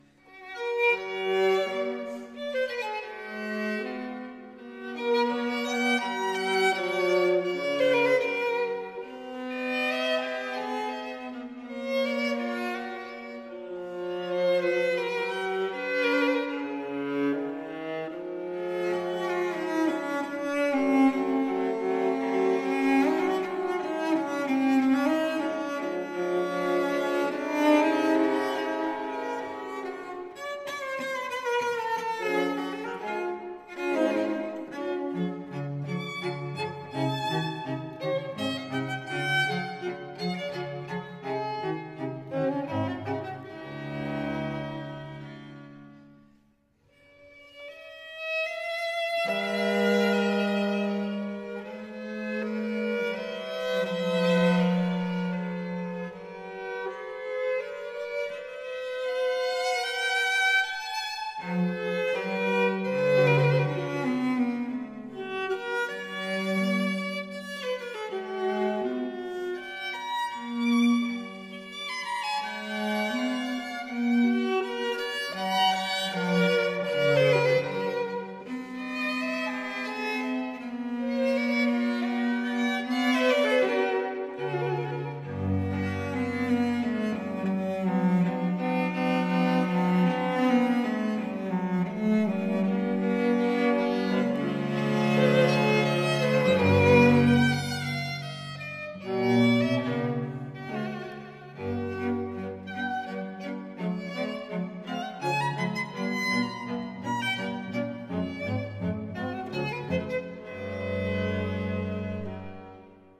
The middle movement is a leisurely, singing Adagio.